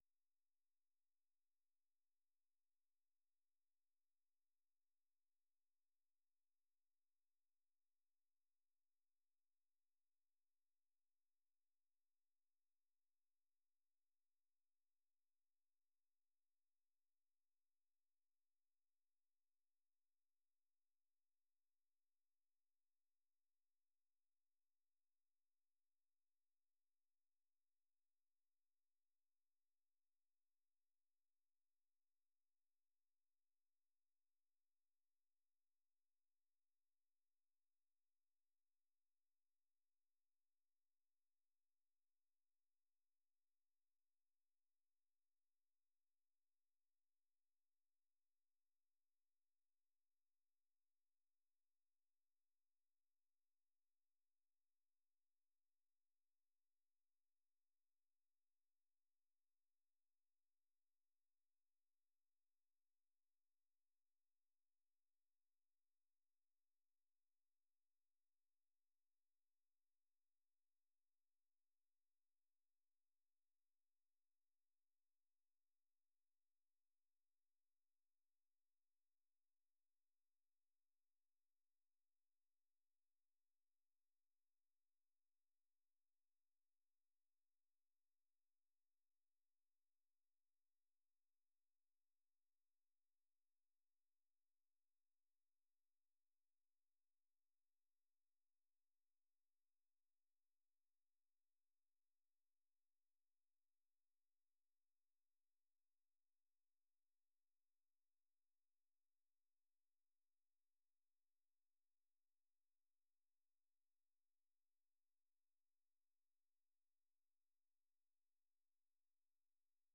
ข่าวสดสายตรงจากวีโอเอ ภาคภาษาไทย 6:30 – 7:00 น.